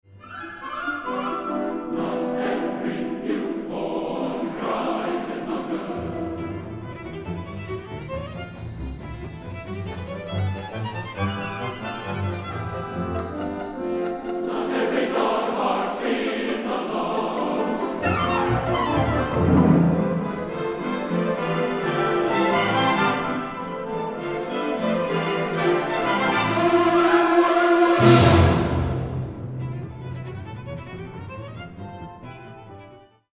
Instrumentation: solo violin, SATB chorus, orchestra